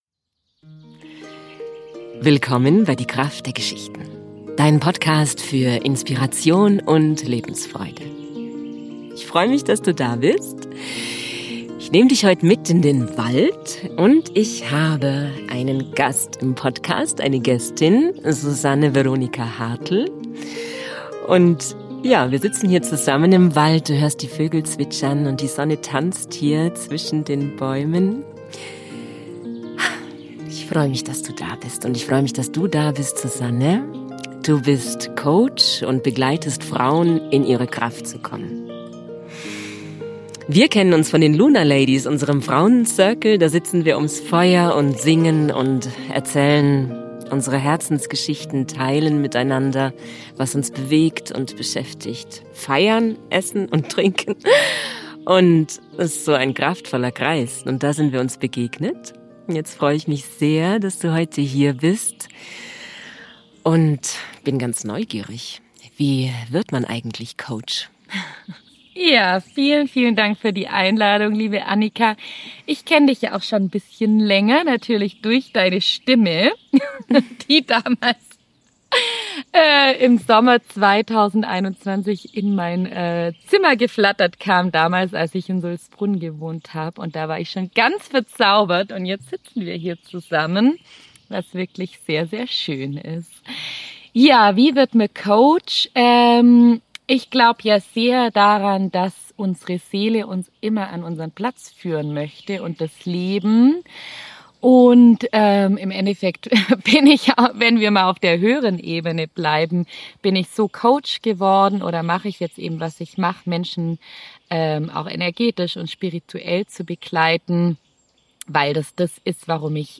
Ganz viel Inspiration mit dem heutigen Interview für Dich!